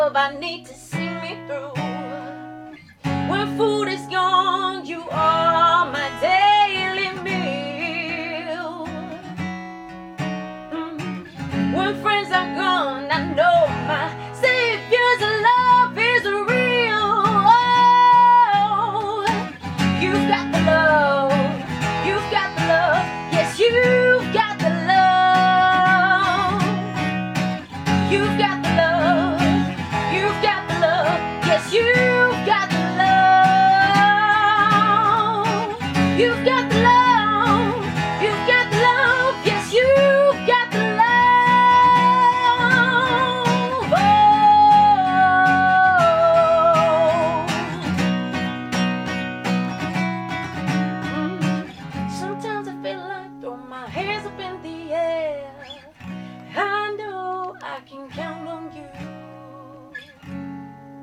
Soul, Rock und Pop
LIVE ACOUSTIC COVER